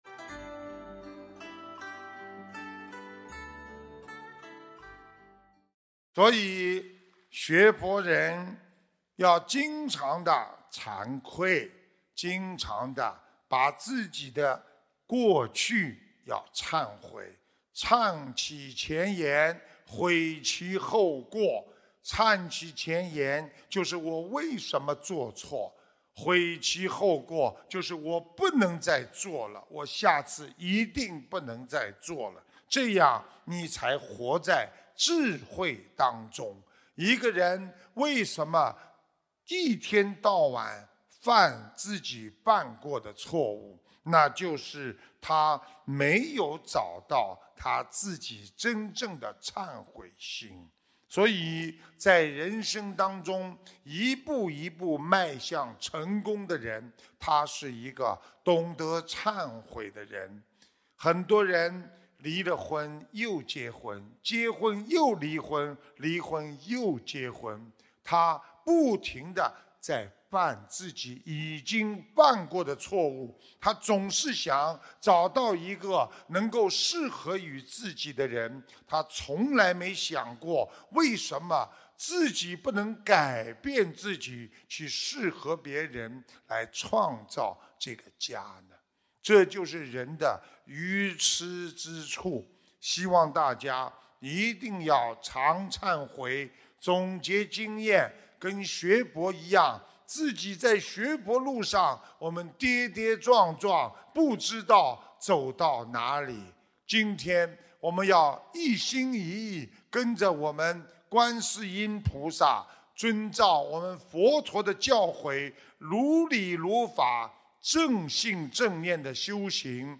视频：7_学佛人要常忏悔；要在人间修成菩萨；修行就是改毛病~中国·澳门 世界佛友见面会 - 法会节选 百花齐放